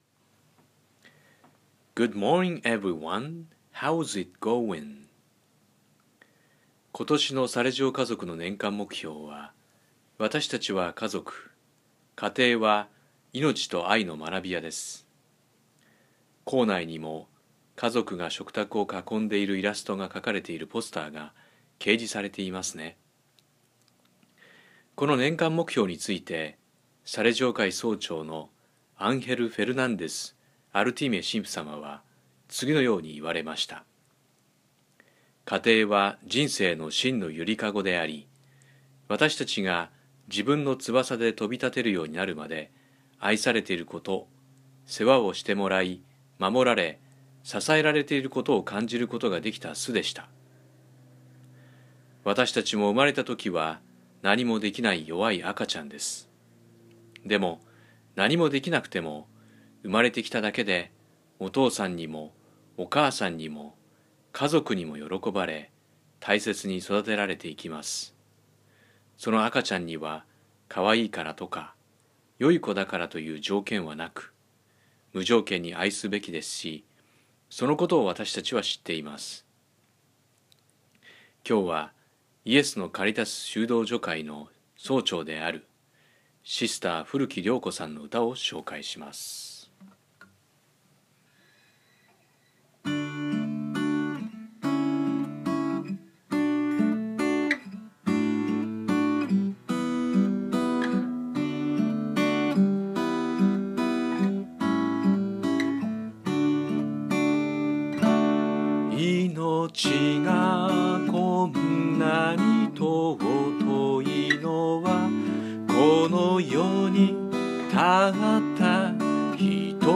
「宗教」の授業や神父による朝の放送、宗教行事を通して徳育に努めています。